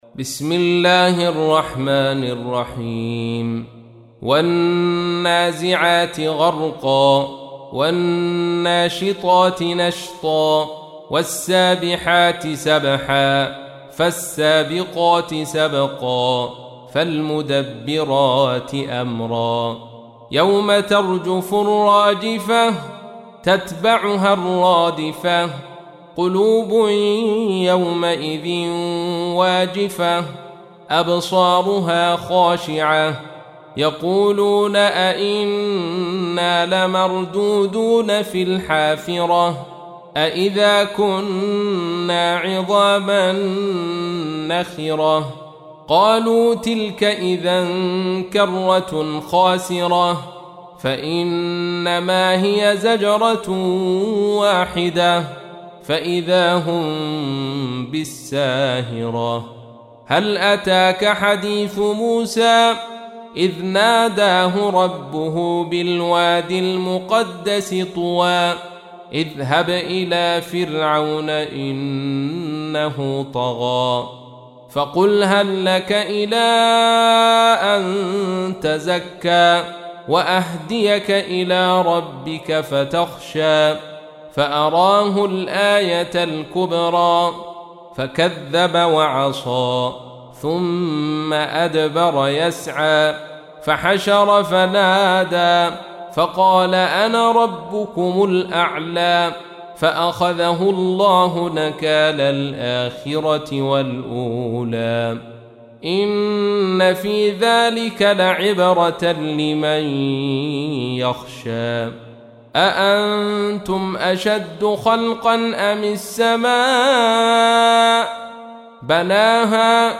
تحميل : 79. سورة النازعات / القارئ عبد الرشيد صوفي / القرآن الكريم / موقع يا حسين